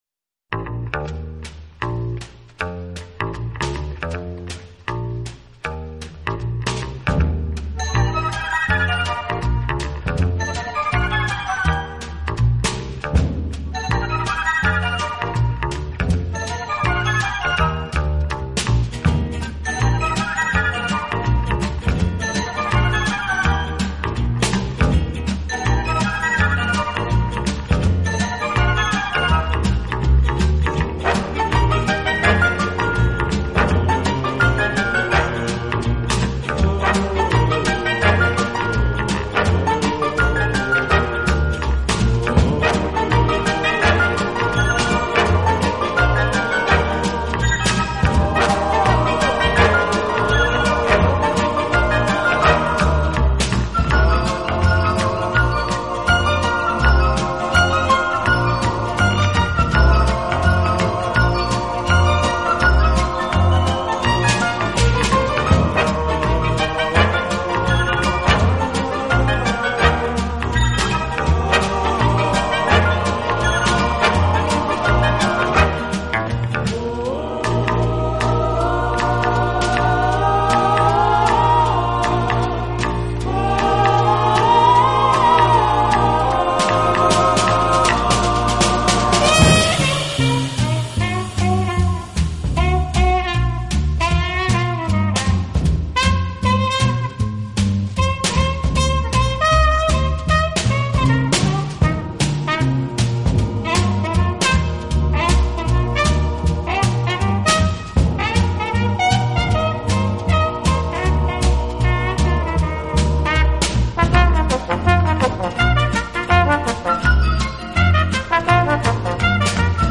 【轻音乐】
曲长均不超过三分钟，曲目风格属于轻快节奏的摇摆风格，很耐听。